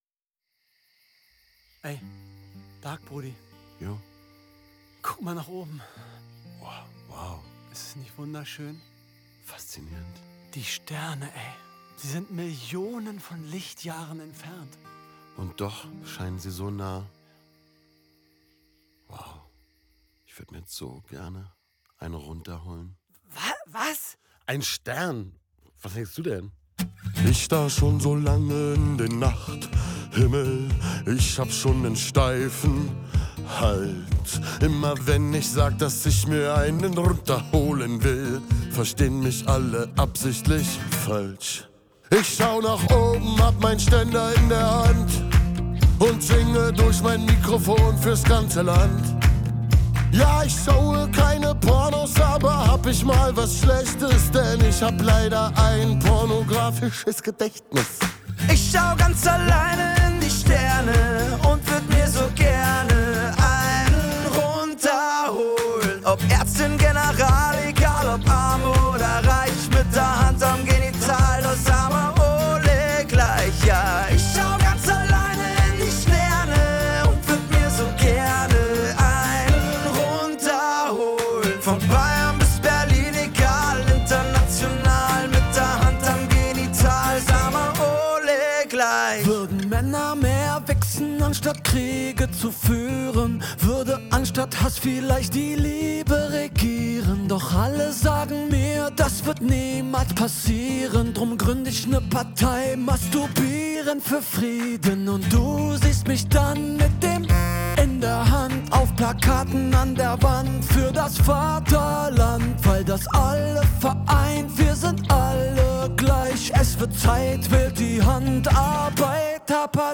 * Track aus Video extrahiert.